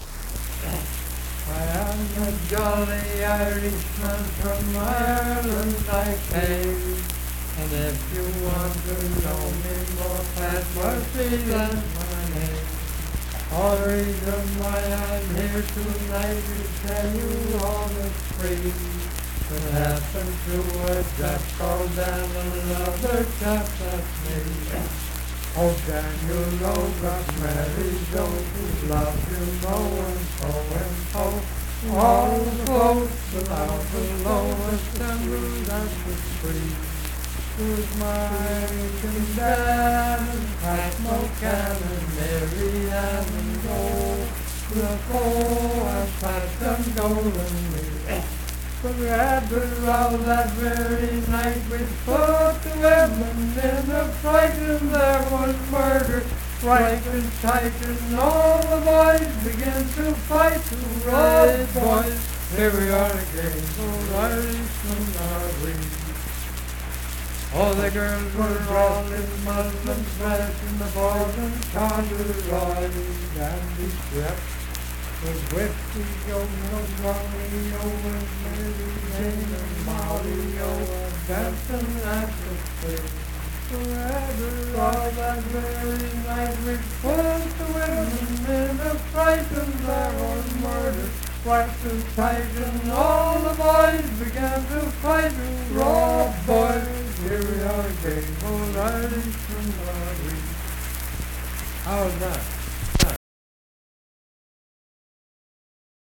Unaccompanied vocal music
Ethnic Songs
Voice (sung)